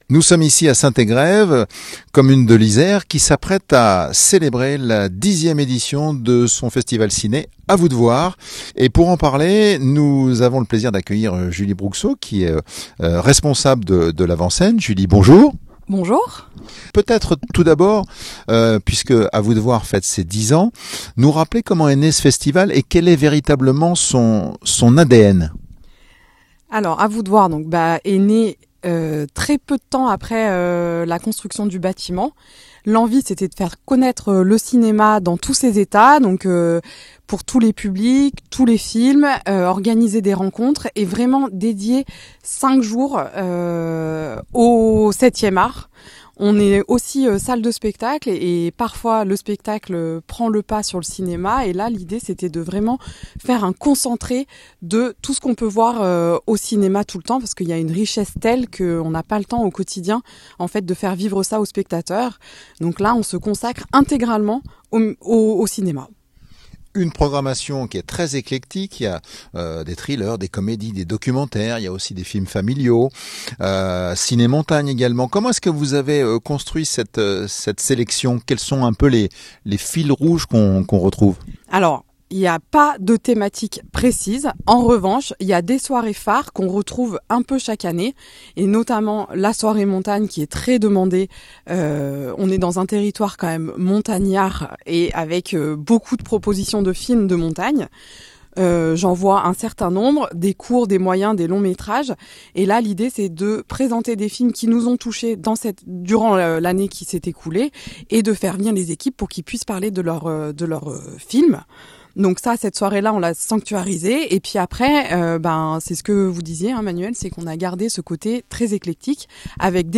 %%Les podcasts, interviews, critiques, chroniques de la RADIO DU CINEMA%%